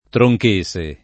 tronchese [ tro j k %S e ] s. f. o m.